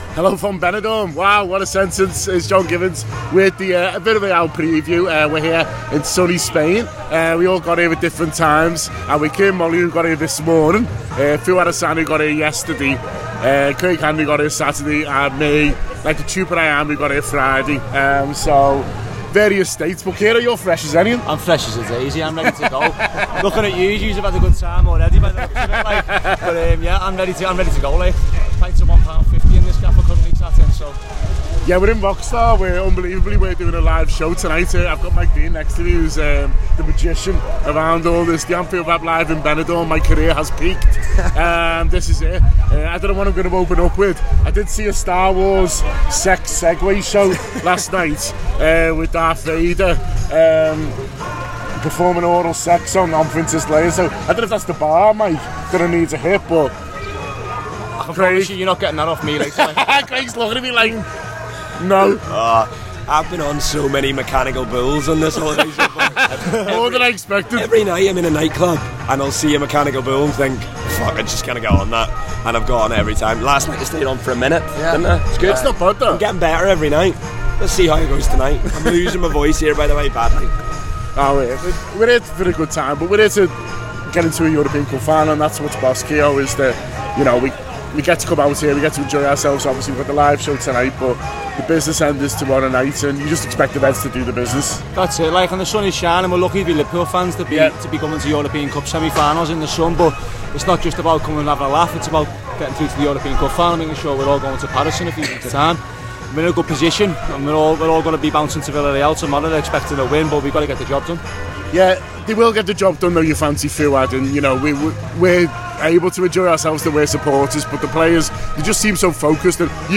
Live From Benidorm: Villarreal Preview